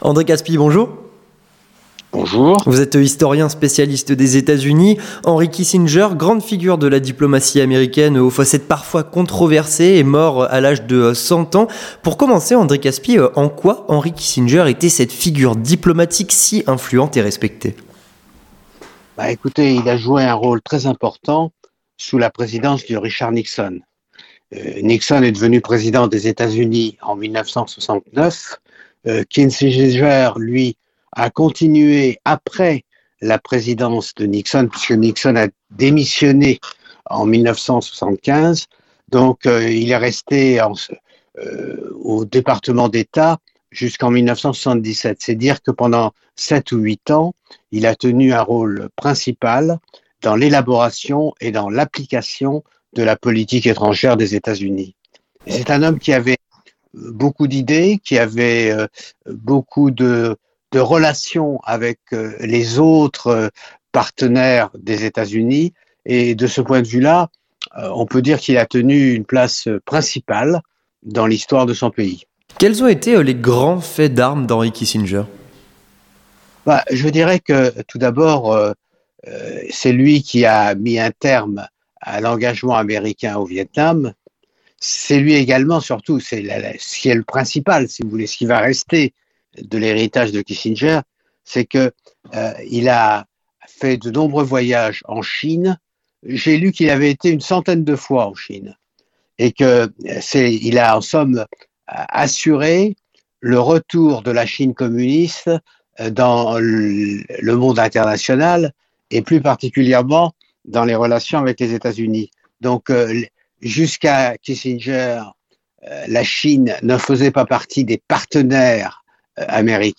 Avec André Kaspi, historien, spécialiste des Etats-Unis